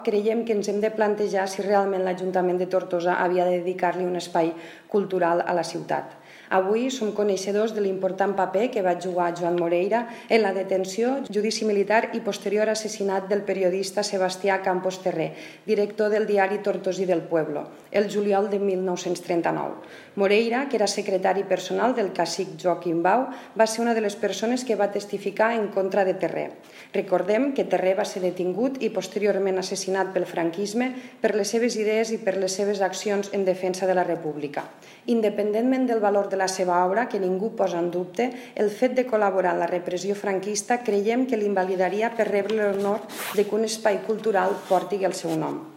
La portaveu de la CUP ha assegurat que no  es vol posar en dubte el valor de l’obra de Moreira, però afirma que “el fet de col·laborar en la repressió franquista, l’invalidaria per rebre l’honor que un espai cultural porti el seu nom”….